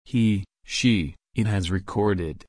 /ɹɪˈkɔːdɪd/